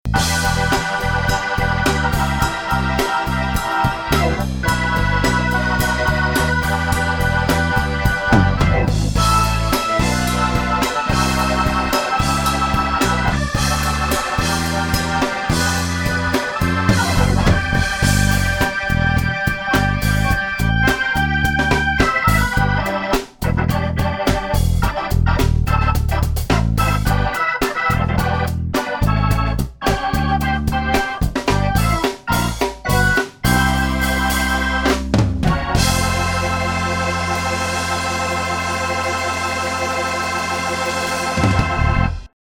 Hammond Orgel - $28.00 (Reg Price: $198)
So gefällt mir die Zerre etwas besser.
Halt ein wenig "breit" angelegt, die Zerre, von daher bin ich gespannt auf die Nummer mit dem zweiten Manual.